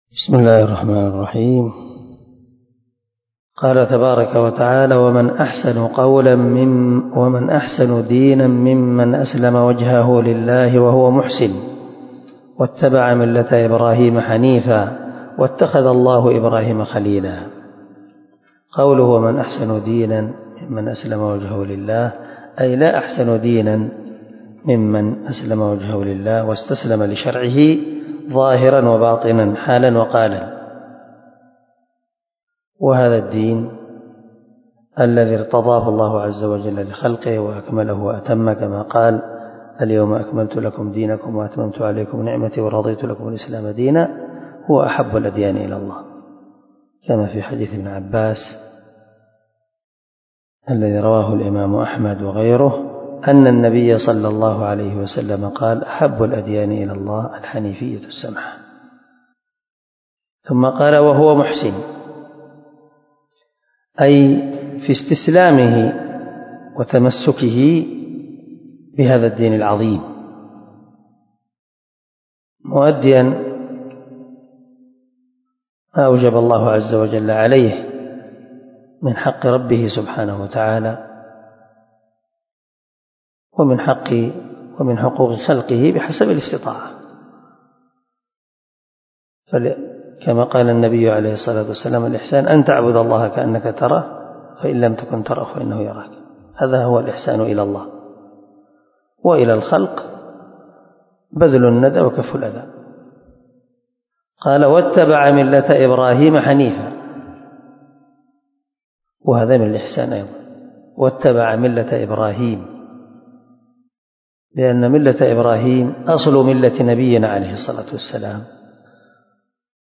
311الدرس 79 تفسير آية ( 125 – 127 ) من سورة النساء من تفسير القران الكريم مع قراءة لتفسير السعدي